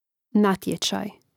Rastavljanje na slogove: na-tje-čaj